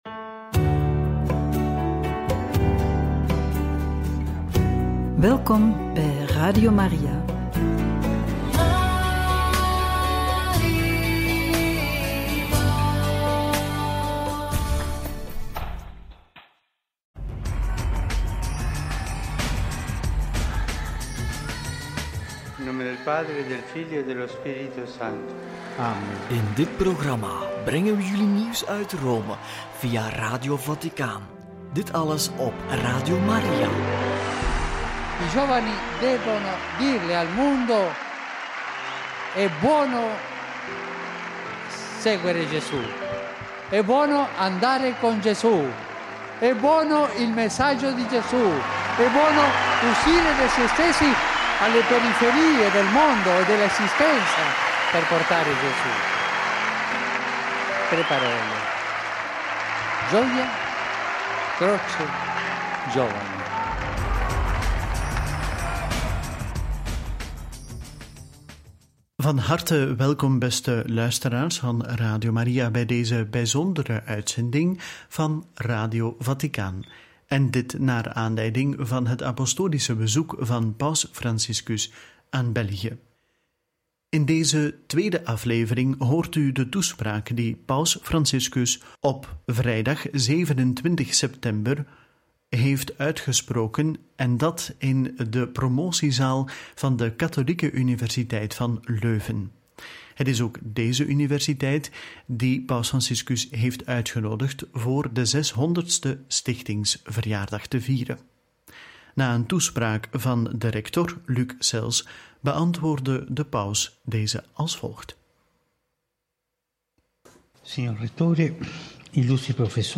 27/9 Paus Franciscus geeft toespraak aan de Katholieke Universiteit van Leuven – Radio Maria
27-9-paus-franciscus-geeft-toespraak-aan-de-katholieke-universiteit-van-leuven.mp3